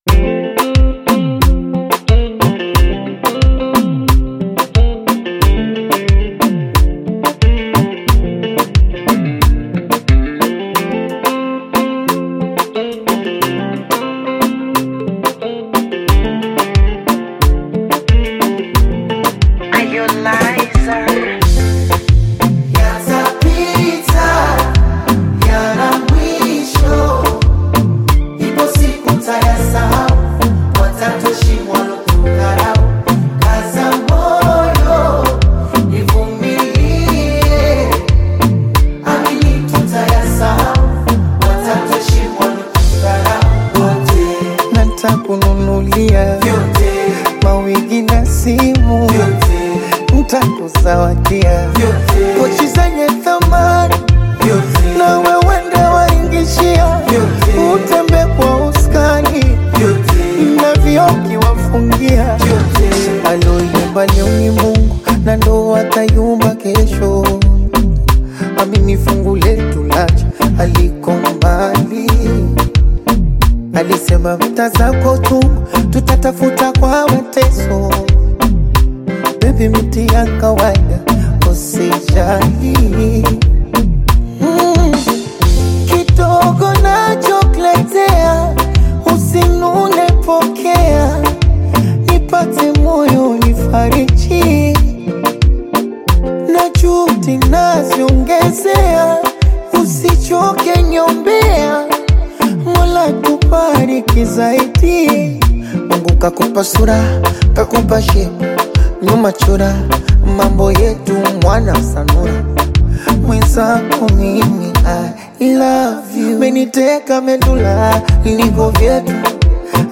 Tanzanian bongo flava artist singer